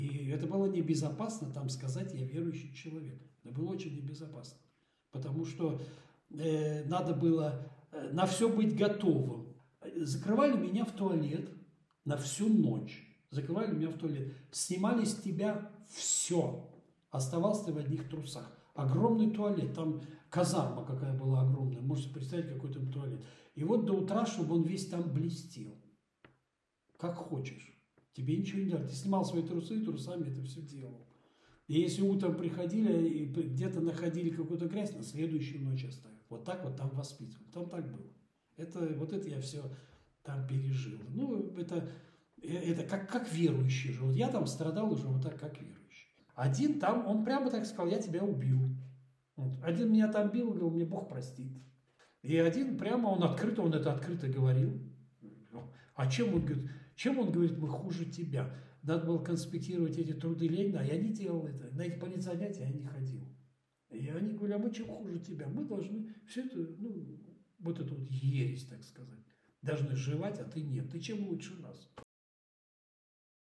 Interviewsequenz Armee